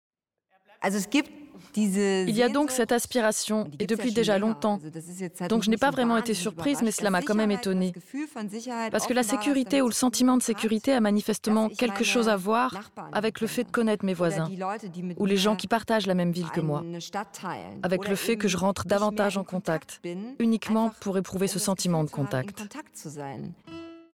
Voix off
Autonome pour enregistrer, chant ou voix, je possède du materiel professionnel (Neumann et Sennheiser)